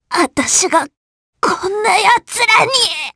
Viska-Vox_Dead_jp.wav